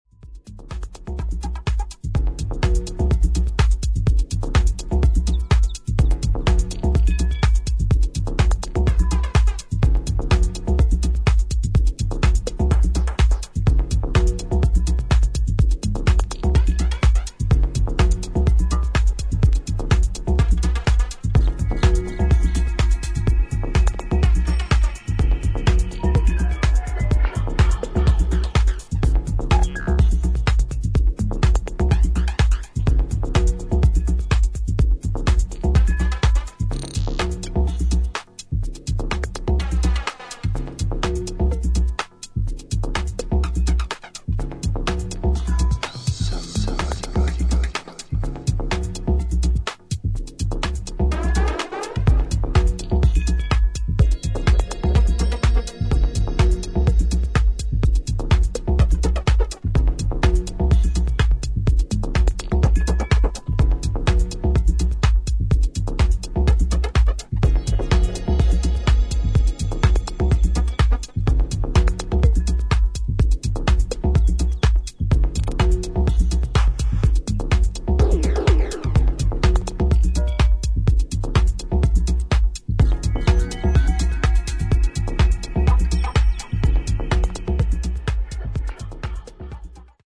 オーガニックなサウンドのシンセ・リードが曲全体を彩る作品となっています